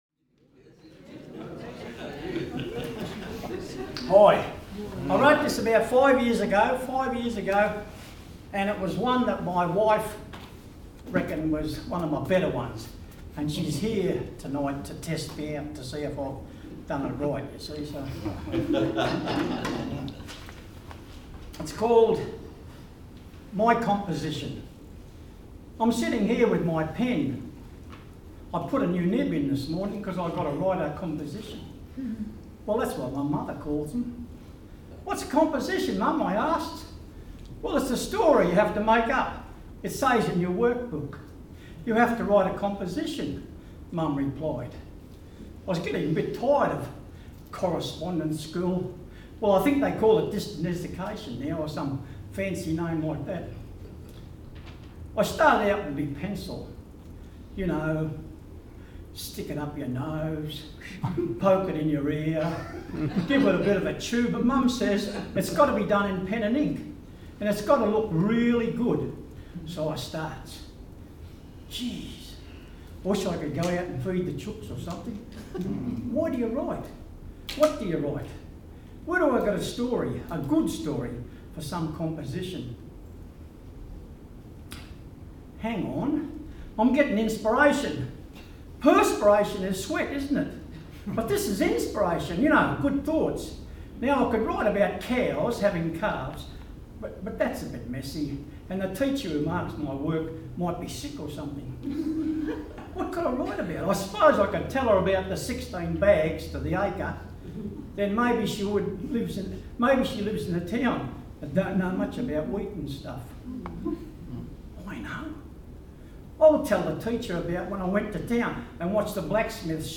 It took place in March 2016 at the Mornington Librarys quarterly ‘Live ‘n Local’ poetry & storytelling session.